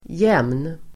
Uttal: [jem:n]